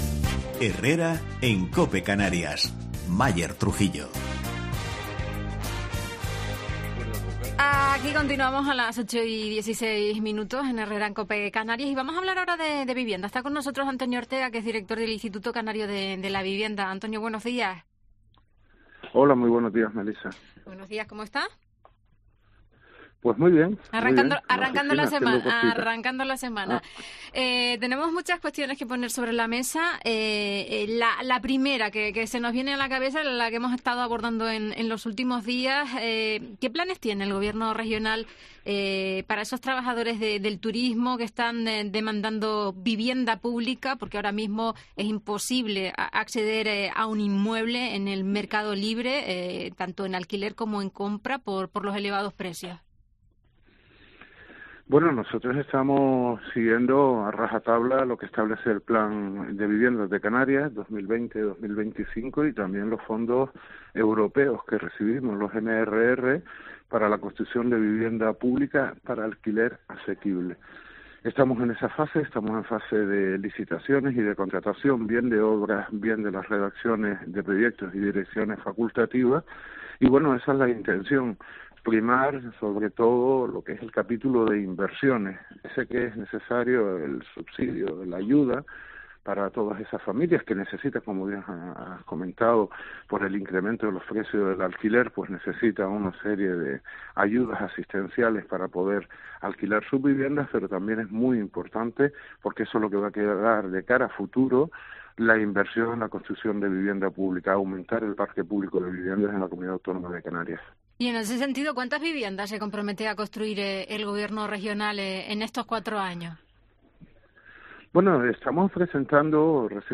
Antonio Ortega, director del Instituto Canario de la Vivienda, habla en COPE del plan de vivienda